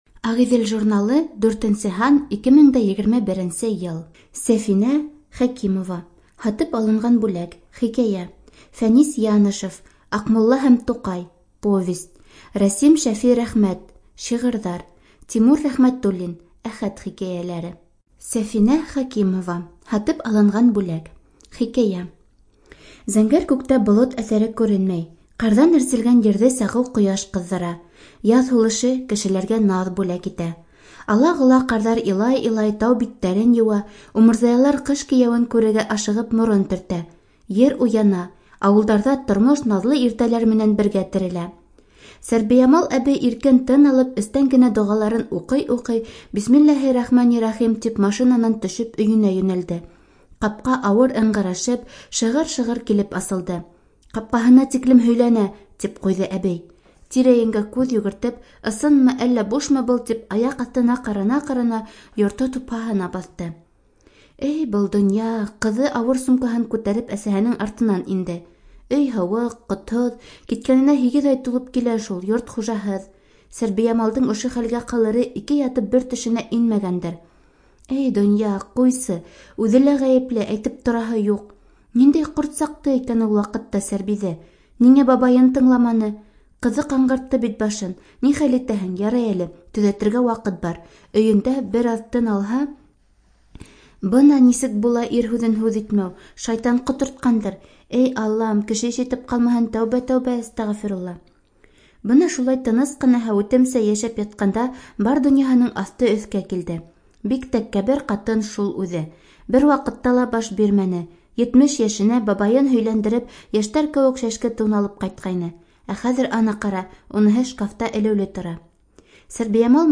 Студия звукозаписиБашкирская республиканская специальная библиотека для слепых